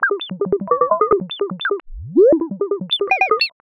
Glitch FX 42.wav